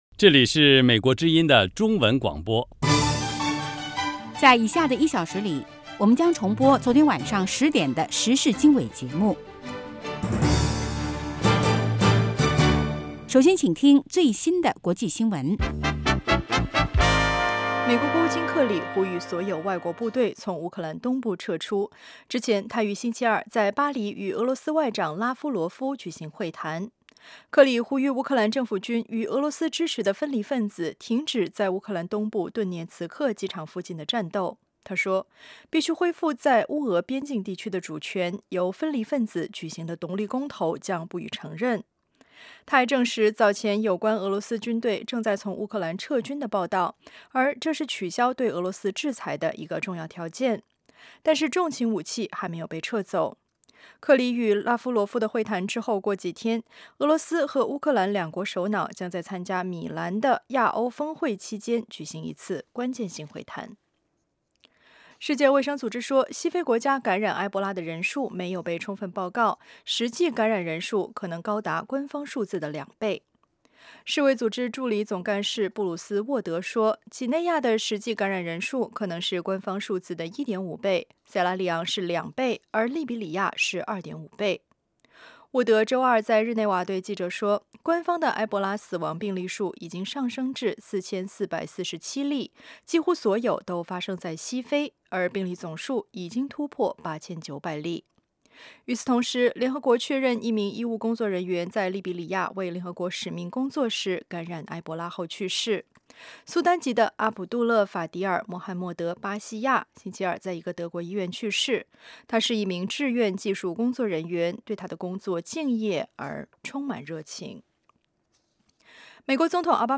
早8-9点广播节目